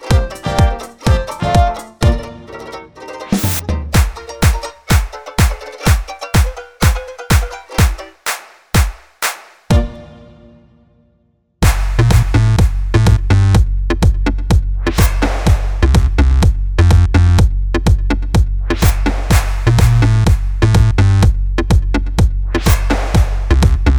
no Backing Vocals R'n'B / Hip Hop 4:40 Buy £1.50